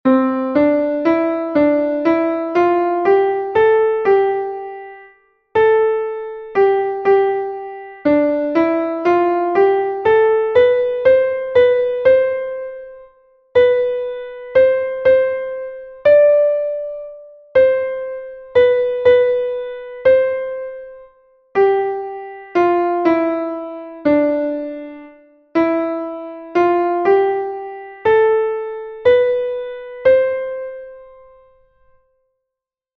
Here, there are two exercises in a 4/4 time signature and one exercise in a 2/4 time signature.